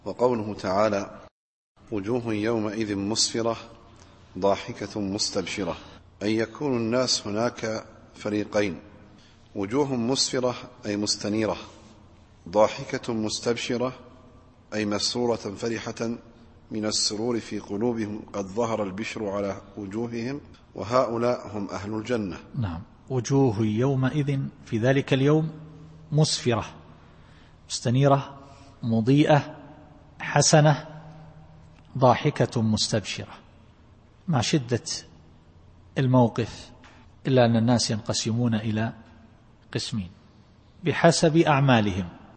التفسير الصوتي [عبس / 38]